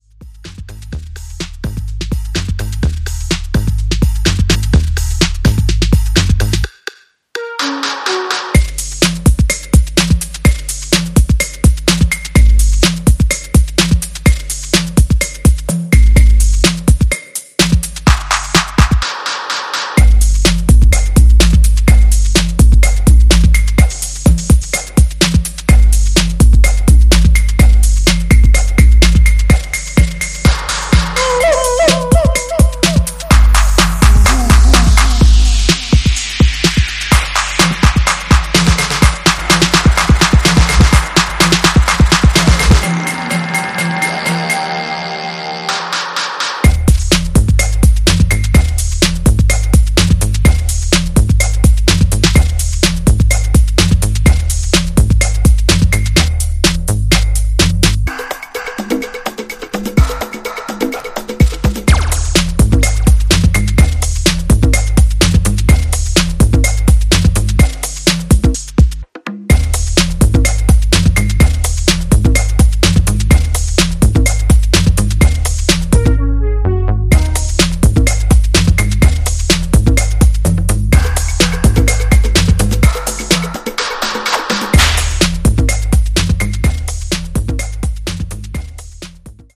本作では、クラシカルなエレクトロや初期UKハウス、アシッド/プロト・ハウス等をオマージュした楽曲を展開。